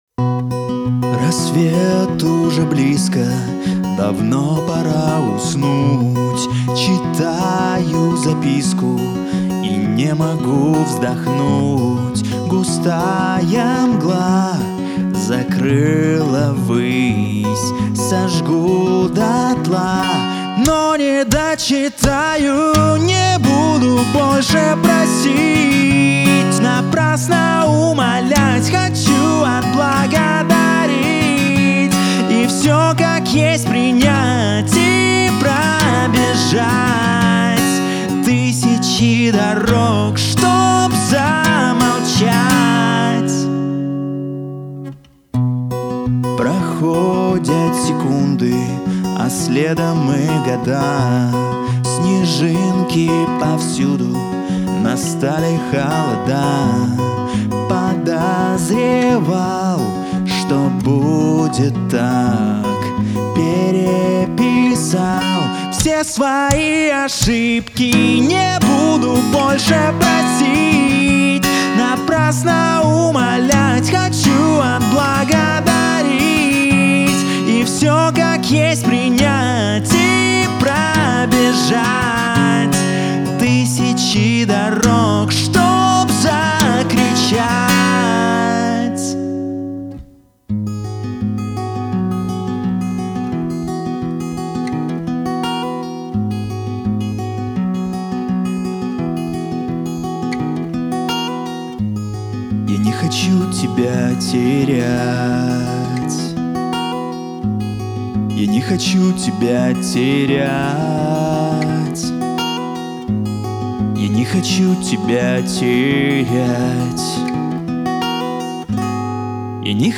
ДЕМО - ВОКАЛ
Муж, Вокал/Молодой